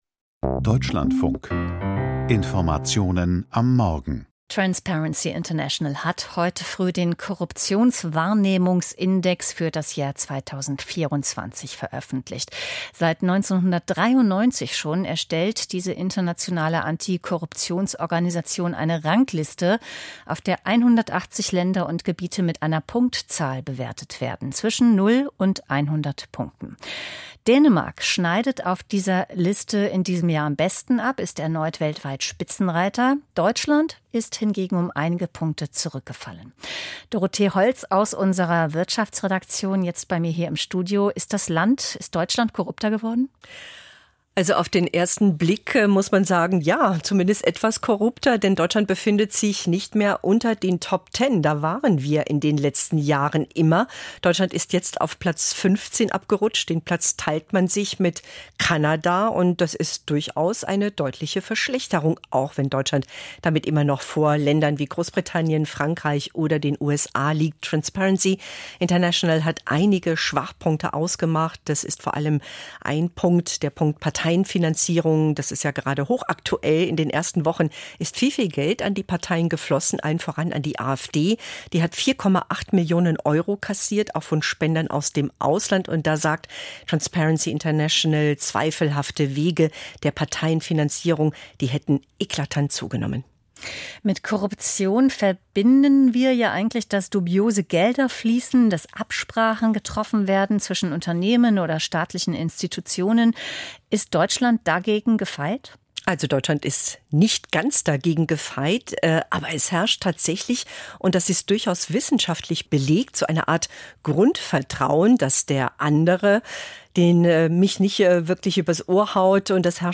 Wirtschaftsgespräch - Korruptionswahrnehmungsindex von Transparency International